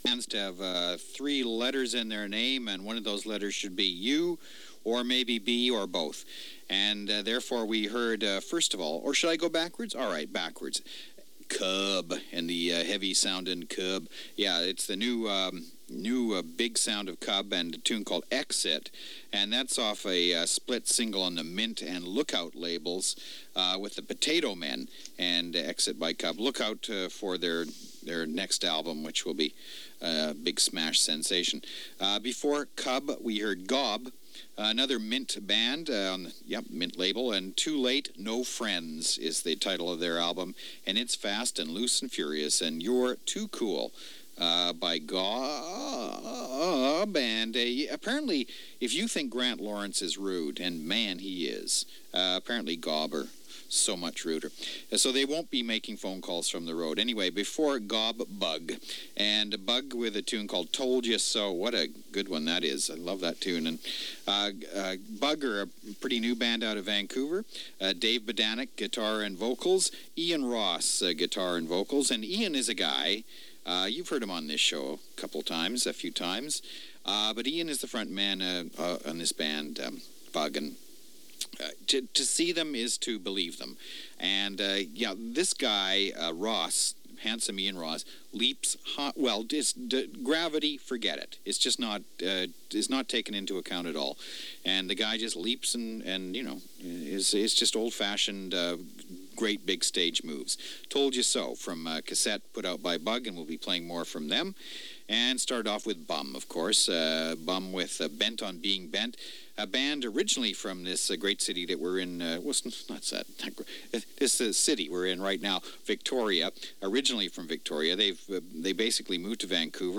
On the weekends they used to have Nightlines in the same time slot. I really liked that show and have even saved a few short air checks of them.